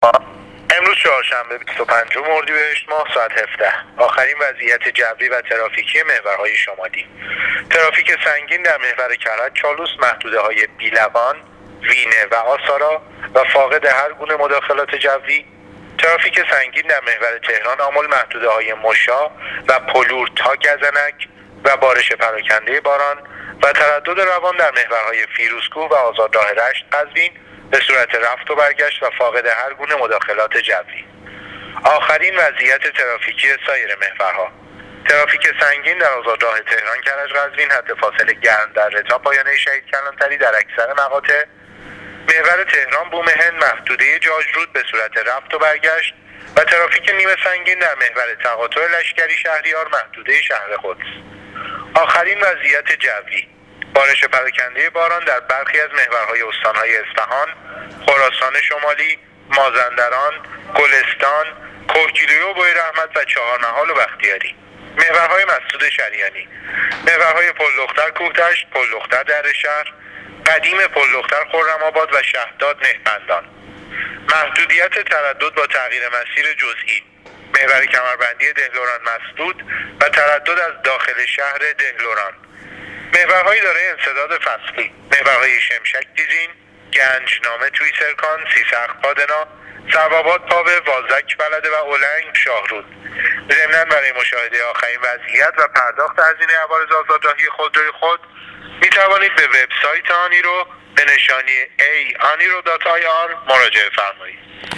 گزارش رادیو اینترنتی وزارت راه و شهرسازی از آخرین وضعیت‌ ترافیکی راه‌های کشور تا ساعت ۱۷ بیست و پنج اردیبهشت/ترافیک سنگین در محور کرج - چالوس و آزادراه تهران - کرج- قزوین